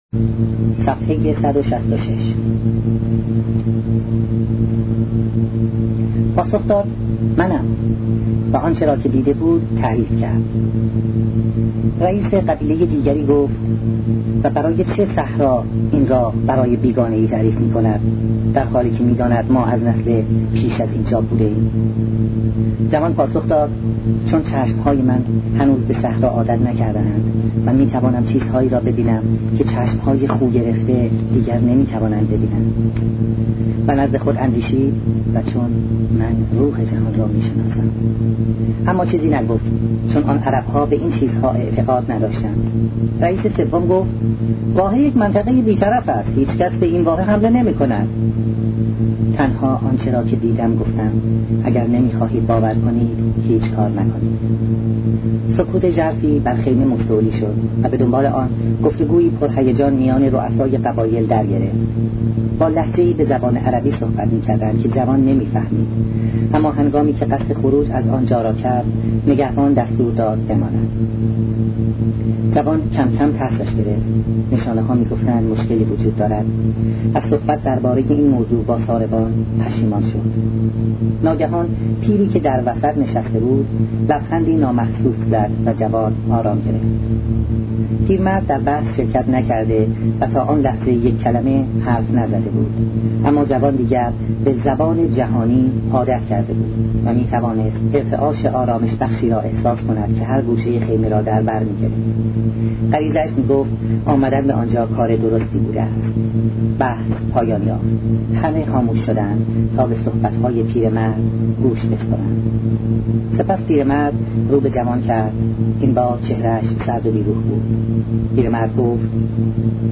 کتاب صوتی کیمیاگر